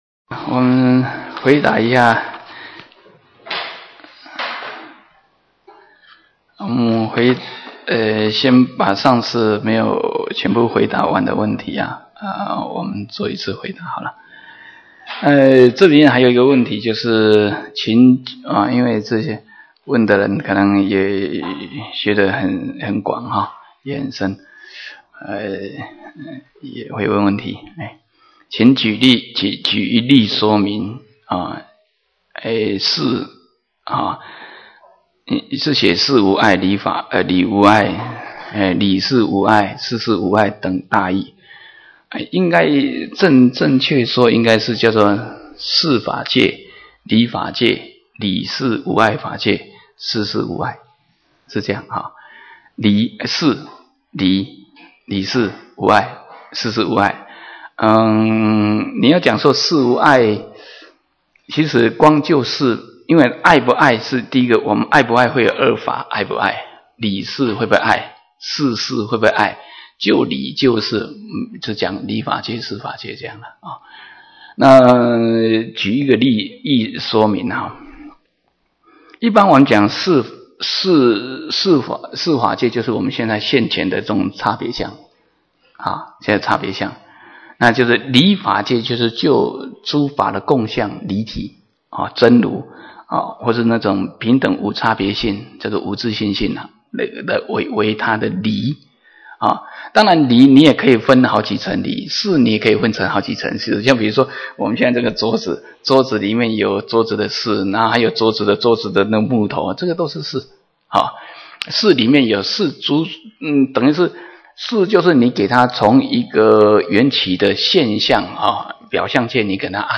瑜伽师地论摄抉择分213(問答).mp3